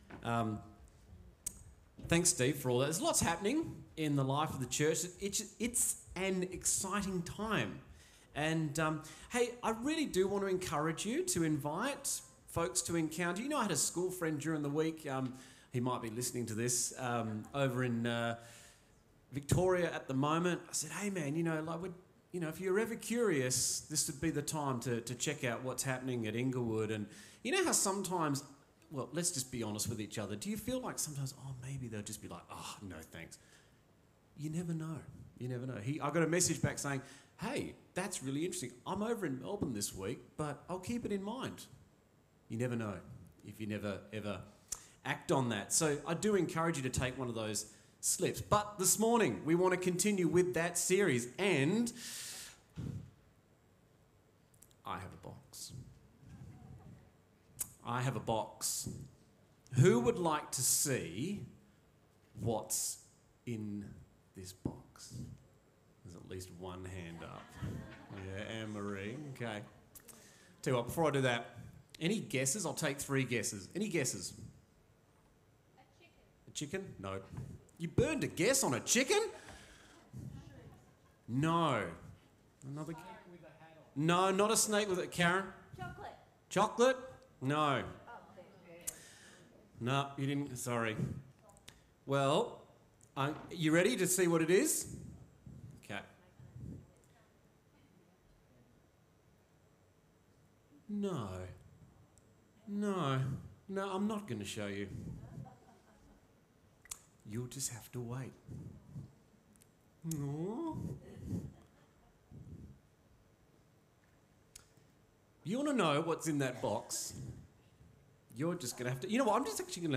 A message from the series "Encounter."